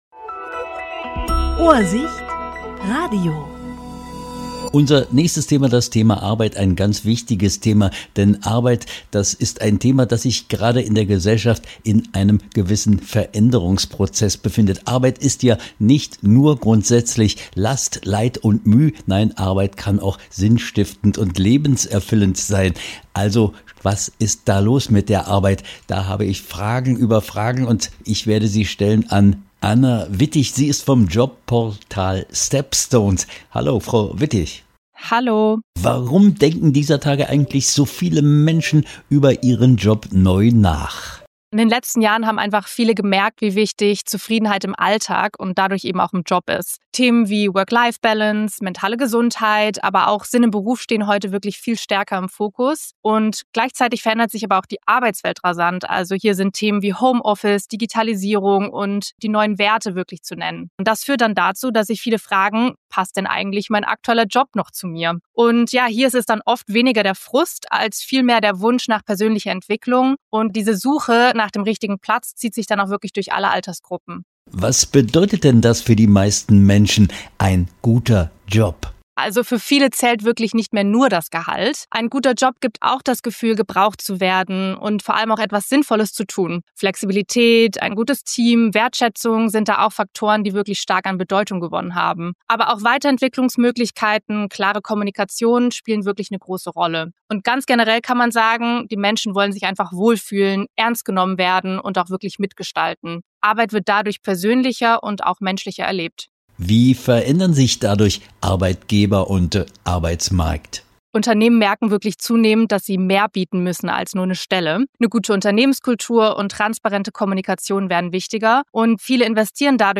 Interview 12.12.2025: Arbeitsmarkttrends